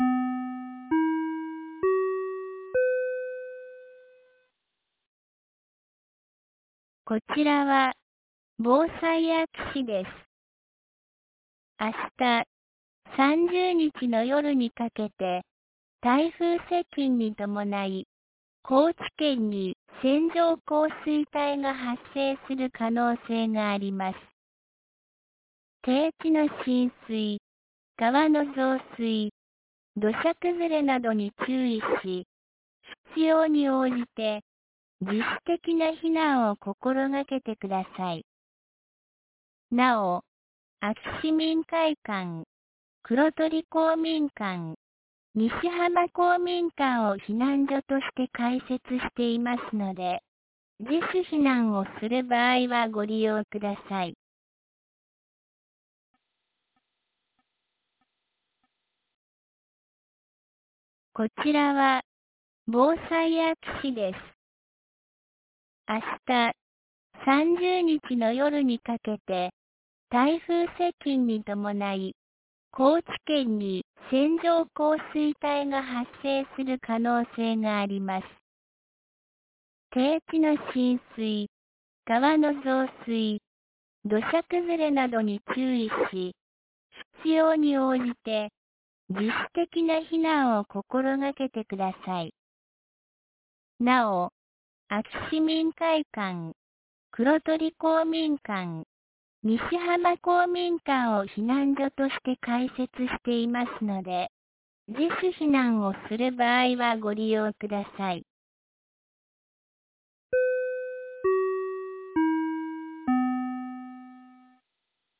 2024年08月29日 15時11分に、安芸市より安芸へ放送がありました。